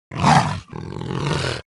Грозный рык леопарда перед атакой